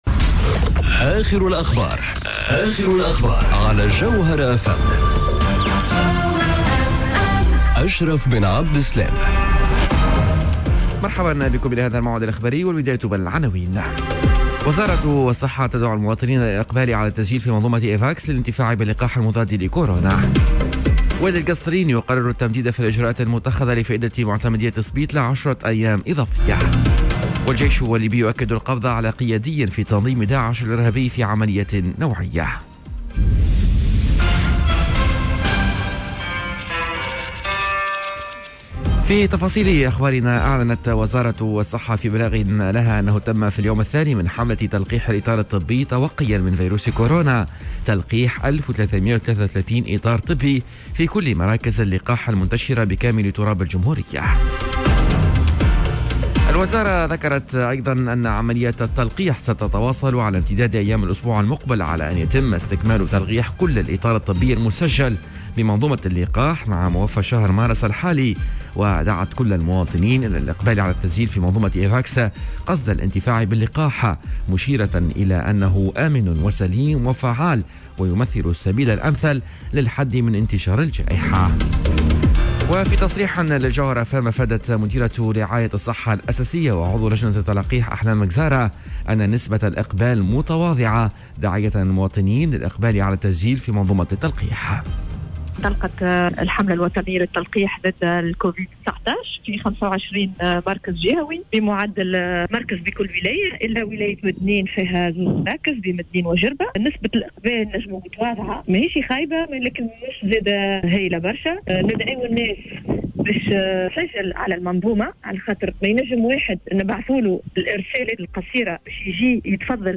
نشرة أخبار السابعة صباحا ليوم الإثنين 15 مارس 2021